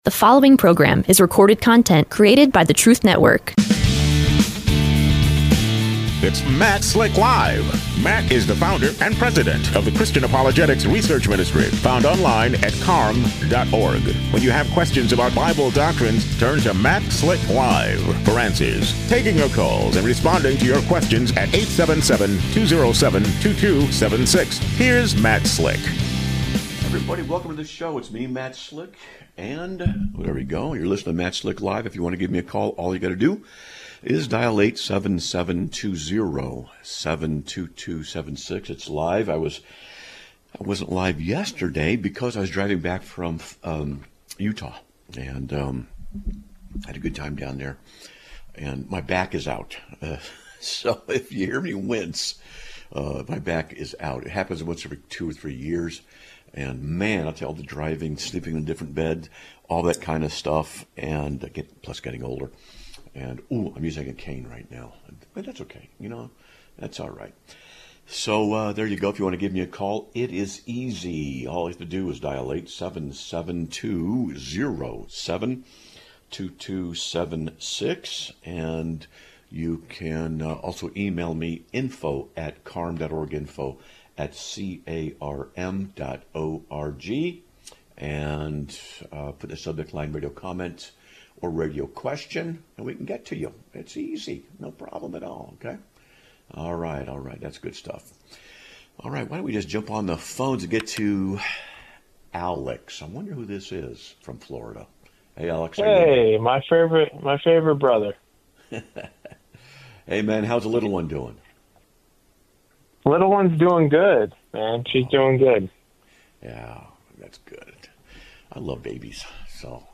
Live Broadcast of 10/07/2025